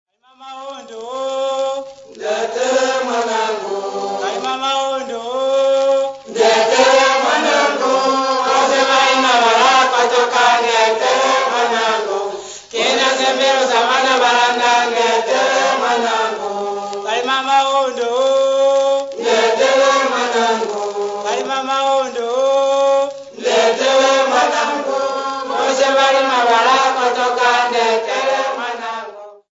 Sons of Barotseland Patriotic Society Choir
Folk music--Africa
Folk songs, Bemba
Field recordings
sound recording-musical
Story song